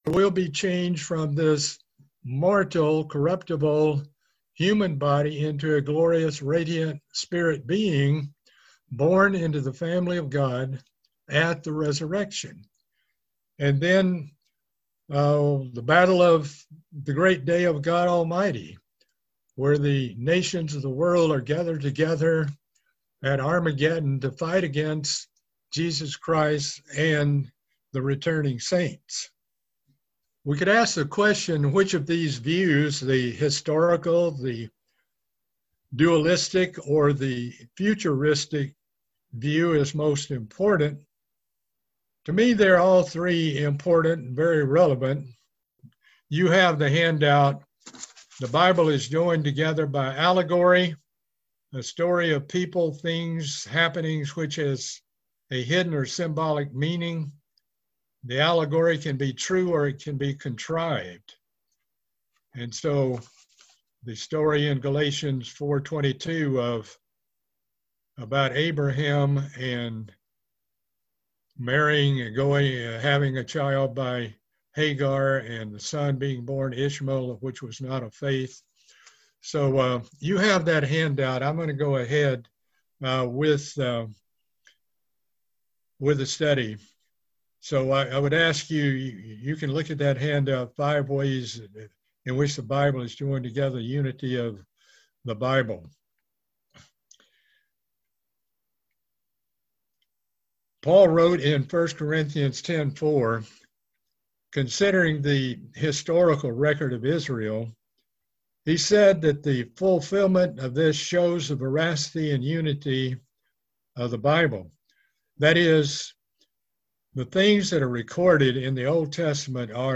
Part 2 of a Bible Study series on the book of Jeremiah